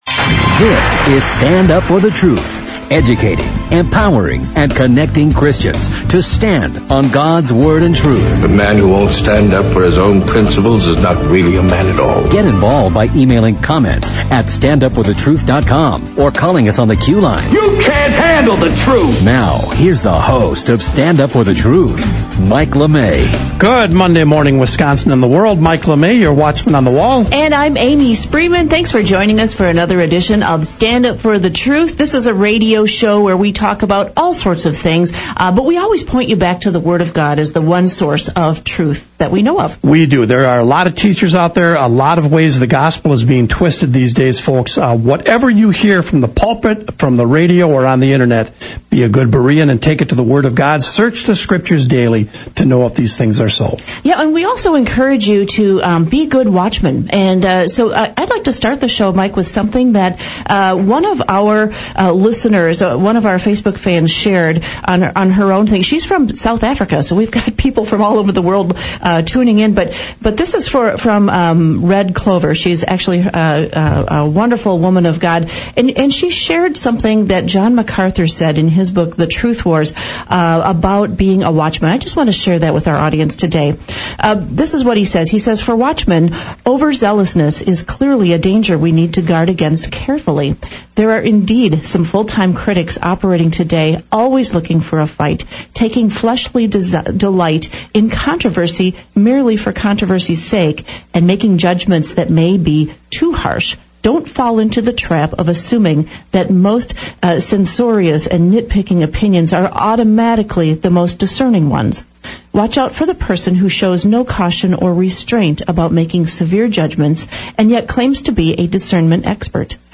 Voices across the Big Wide Ocean and me reading The Truth War of John MacArthur